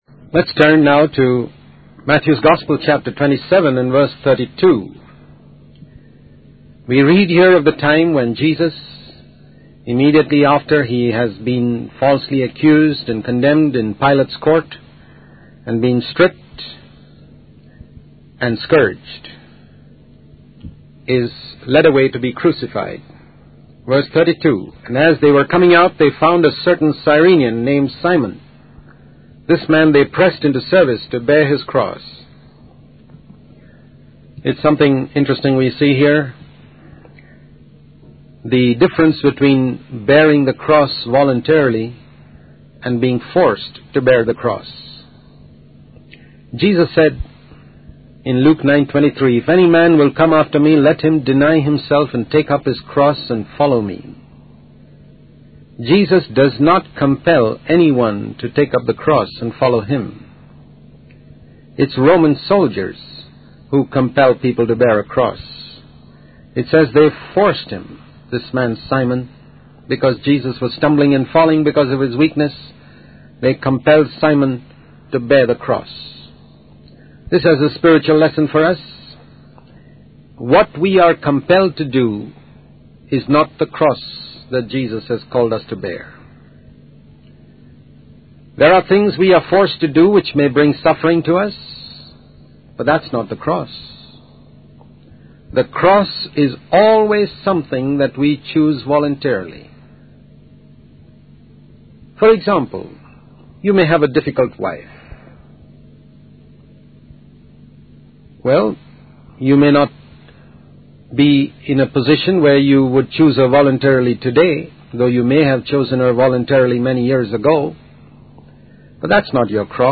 In this sermon, the speaker emphasizes the importance of understanding the depth and meaning of the commission mentioned in Mark 16:15-16.